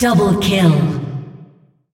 double-kill-2.mp3